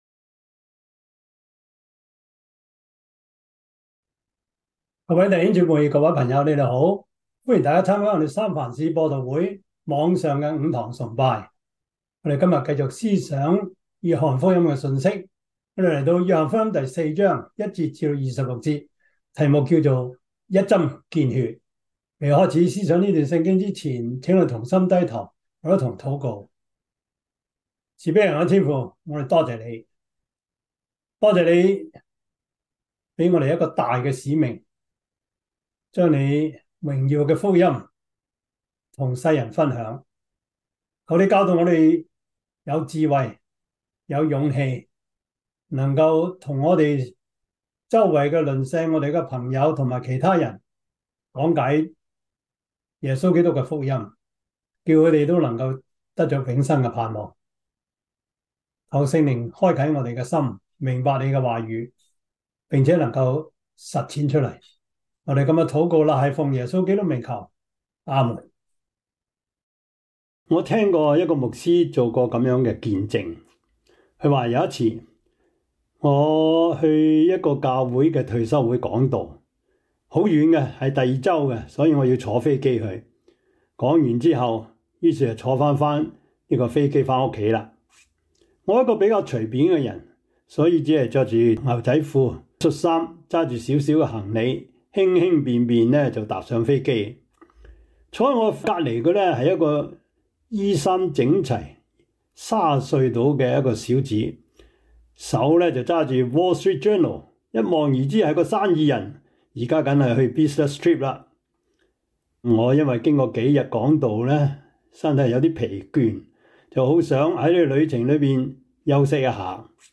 」 Topics: 主日證道 « 耶穌基督的好消息 基礎神學 (二) – 第七課 »